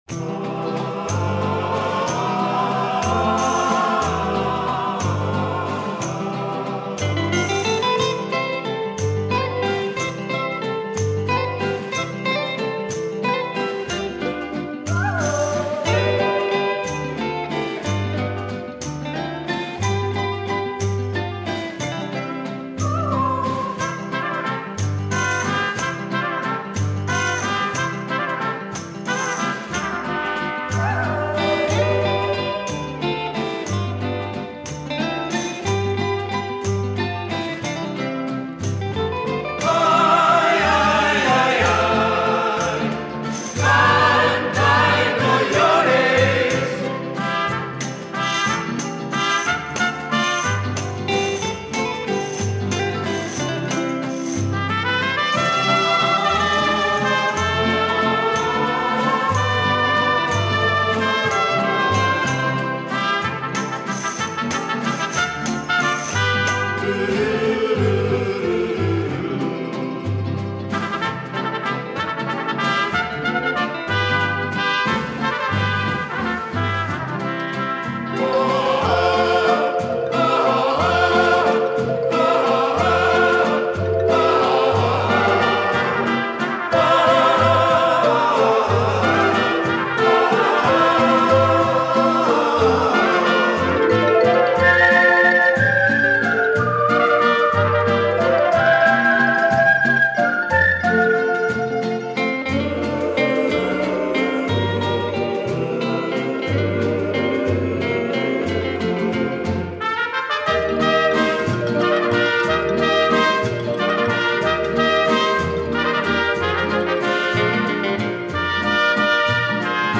Музыквльная специальность - труба